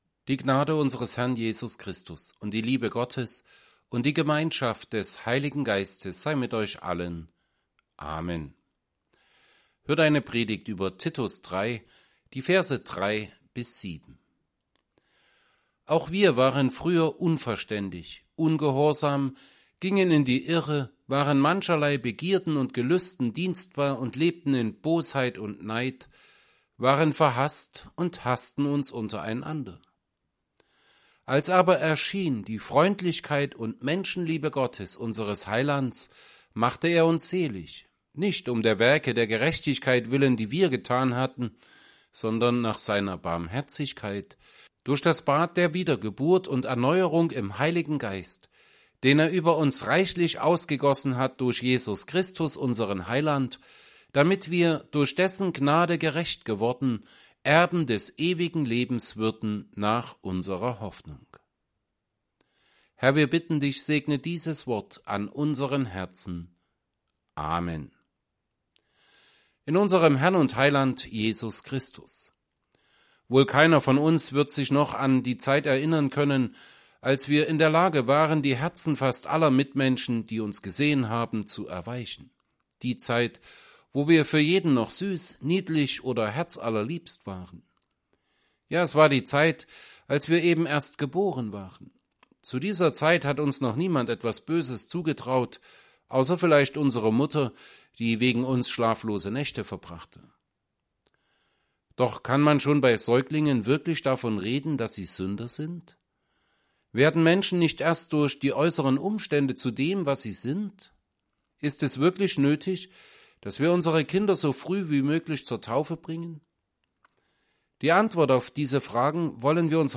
Predigt zu Titus 3,3-7.mp3